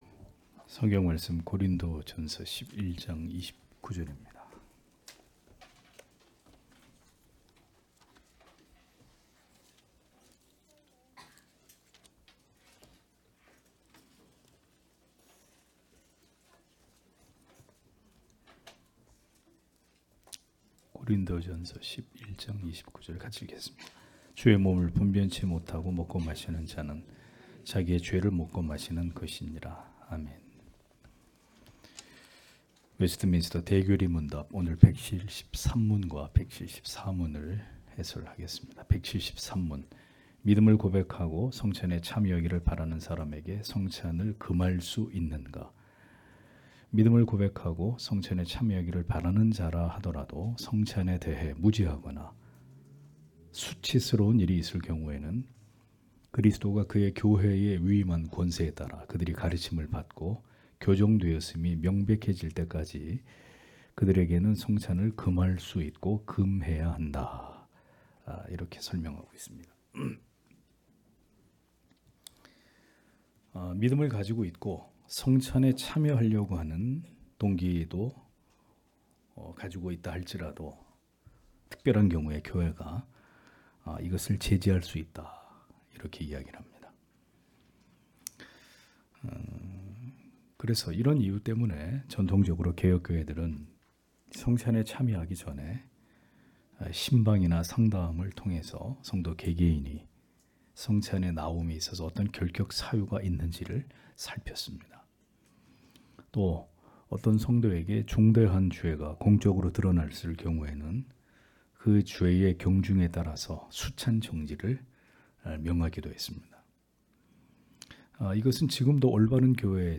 주일오후예배 - [웨스트민스터 대요리문답 해설 173-174] 173문) 신앙을 고백하는 성찬을 받고 싶어 하는 자에게 성찬을 못 받게 할 수있을까? 174문) 성찬식 거행 당시에 성례를 받는 자에게 요구되는 것은 무엇인가? (고전11장 29절)
* 설교 파일을 다운 받으시려면 아래 설교 제목을 클릭해서 다운 받으시면 됩니다.